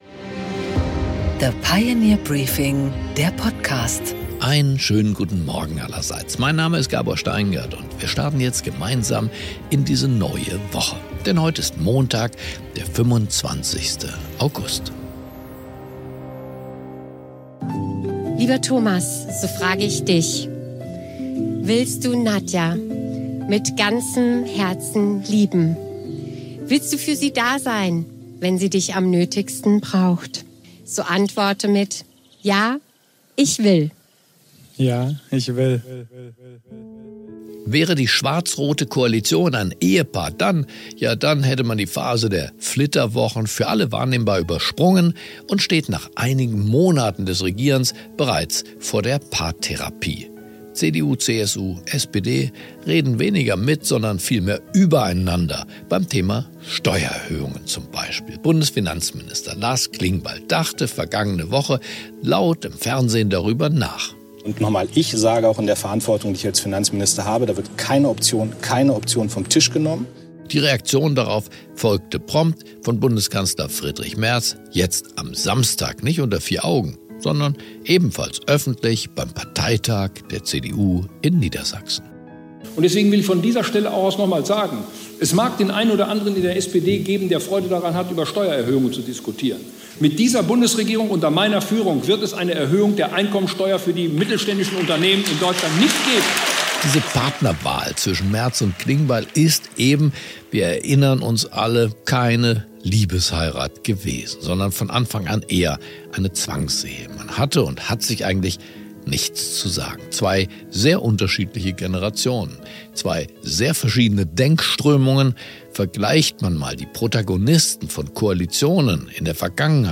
Gabor Steingart präsentiert das Pioneer Briefing